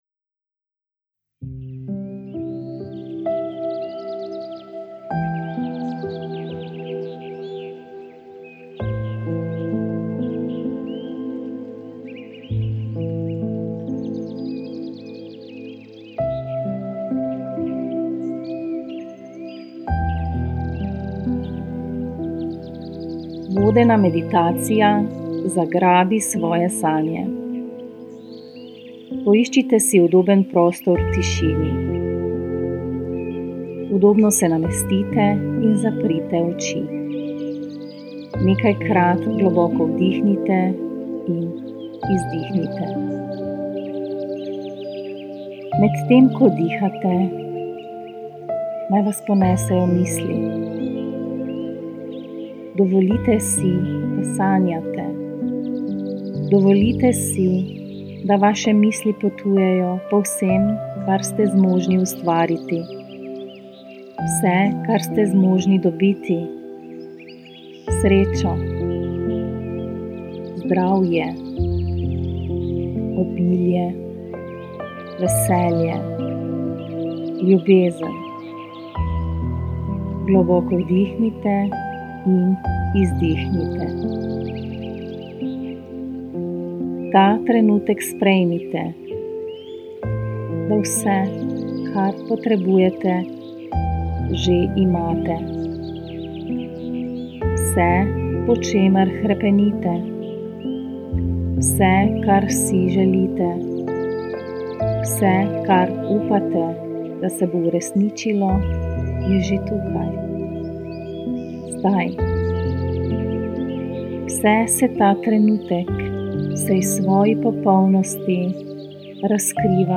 Meditacija Zagrabi svoje sanje